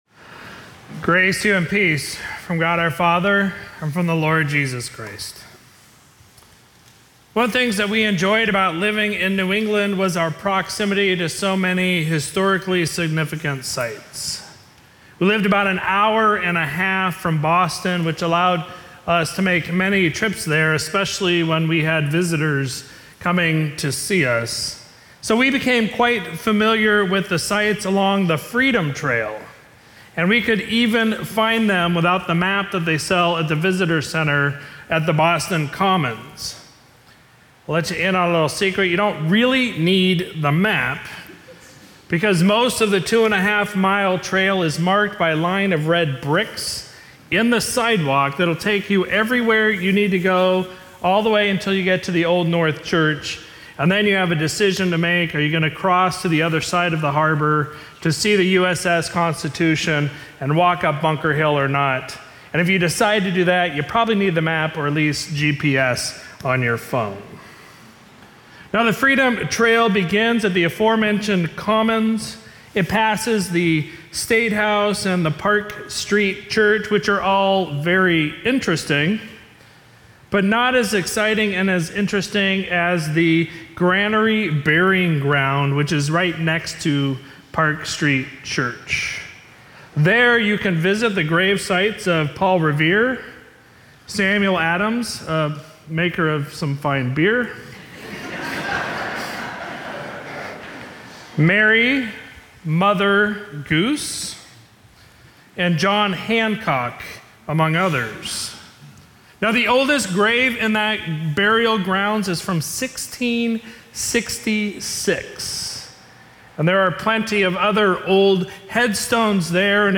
Sermon for Sunday, July 2, 2023